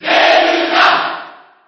File:Zelda Cheer JP SSB4.ogg